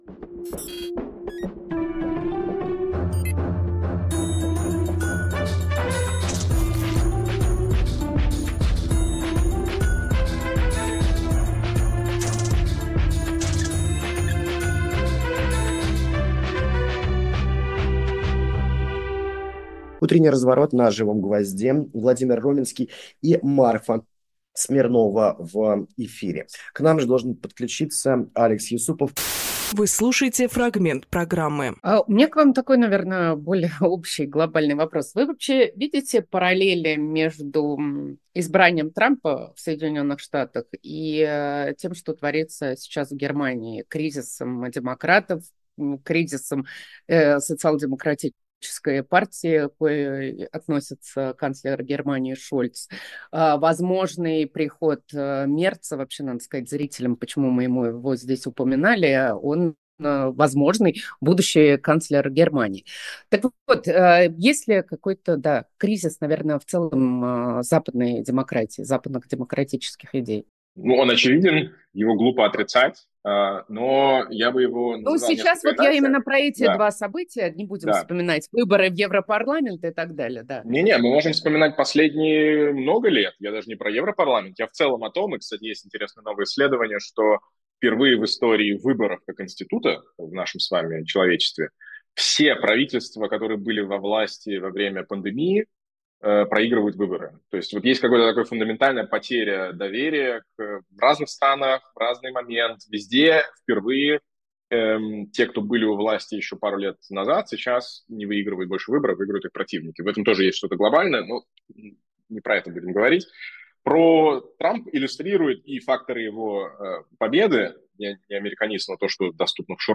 Фрагмент эфира от 11.11